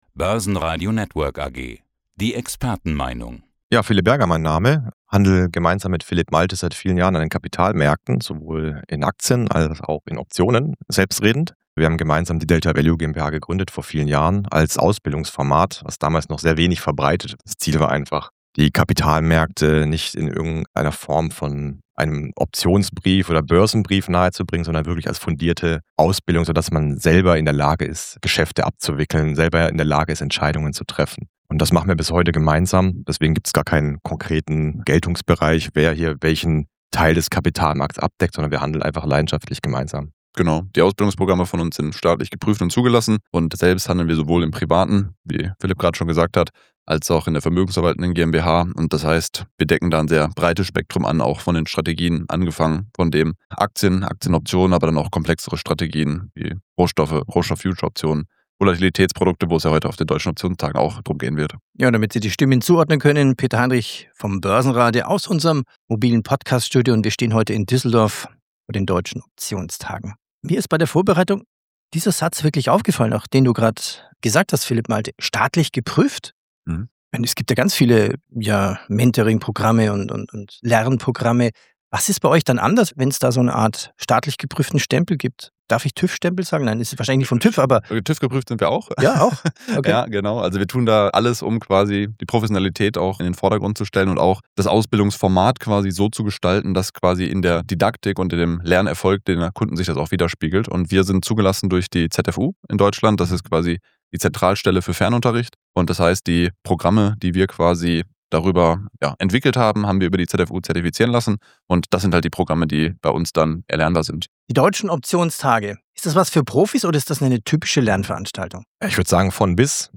Willkommen zu einem neuen Interview des Börsenradios – heute direkt aus Düsseldorf von den Deutschen Optionstagen 2026.